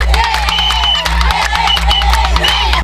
DANCE 09.A.wav